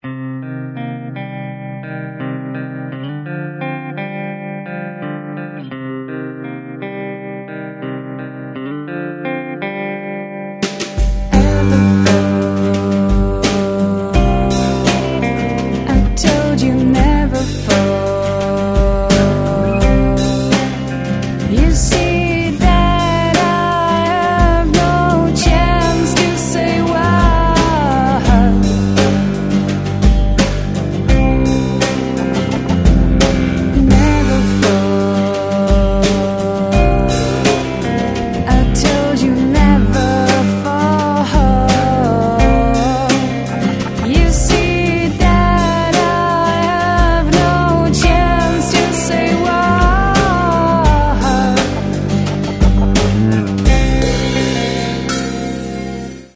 nezávislá kytarová skupina
bicí
zpěv
baskytara
kytary